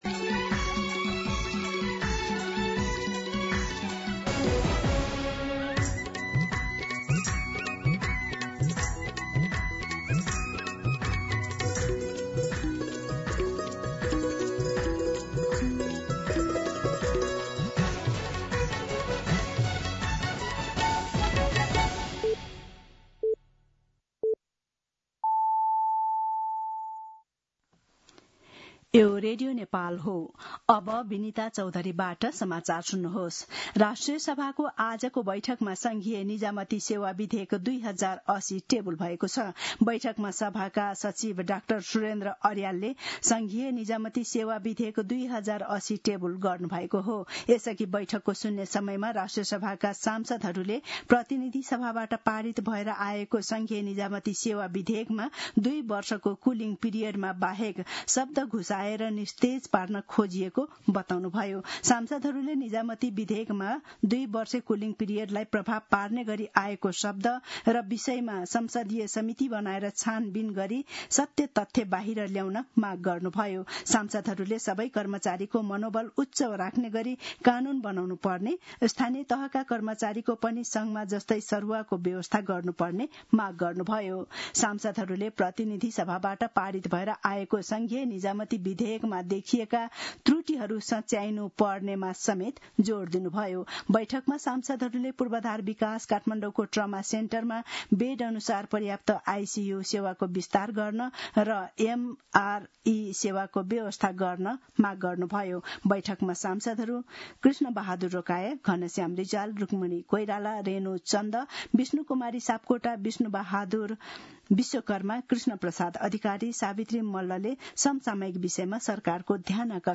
मध्यान्ह १२ बजेको नेपाली समाचार : १८ असार , २०८२